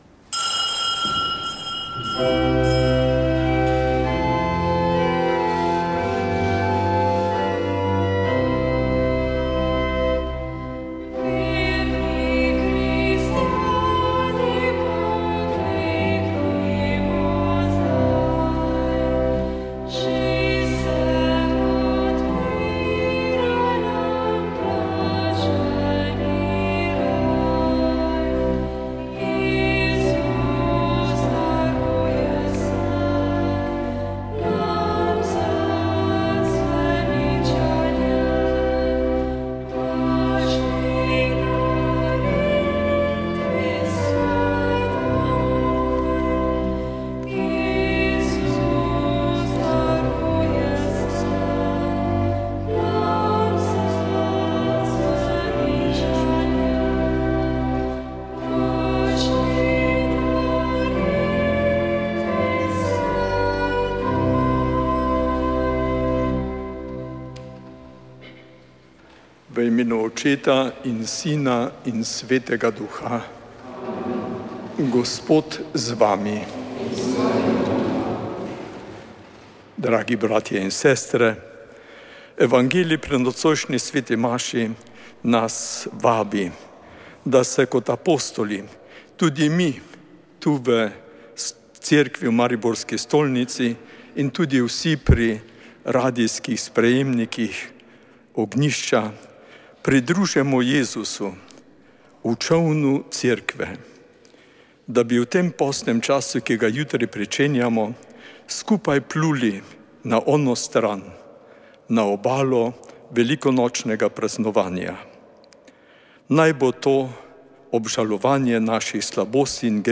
Sveta maša
Sv. maša iz bazilike Marije Pomagaj na Brezjah 18. 5.